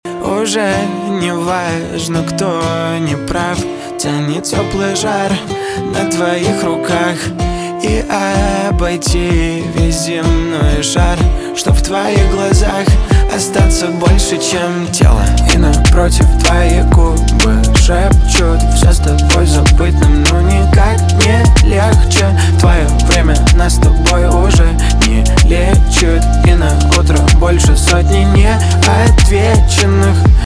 поп
нарастающие , битовые
гитара , грустные